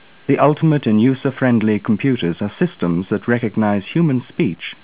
The demo below contains a sample of a male voice originally sampled at 8kHz with 16 bit samples.
8 bit linear
m1_8k_pcm8.wav